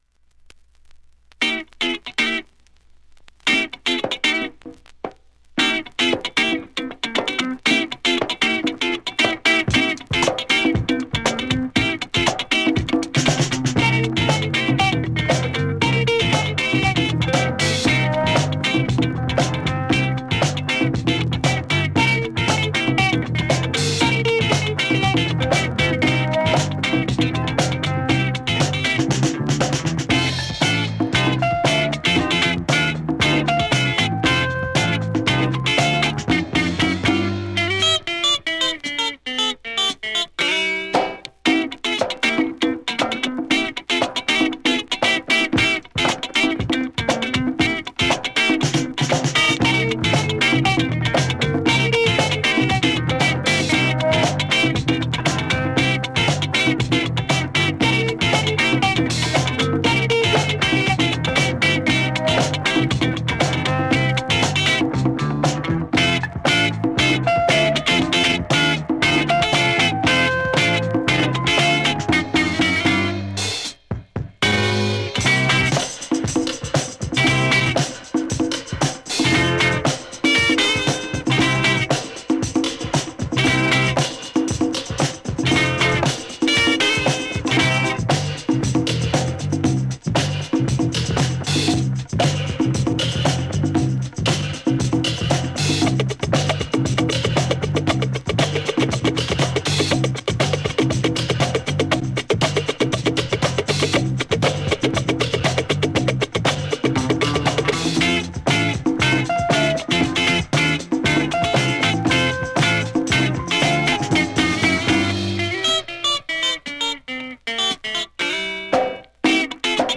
現物の試聴（両面すべて録音時間5分14秒）できます。
(Instrumental)
切れ味鋭いファンキーなギターに
疾走感ある乾いたドラム、
砂埃舞うファンキーなレアグルーヴチューン